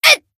BA_V_Neru_Bunny_Battle_Damage_2.ogg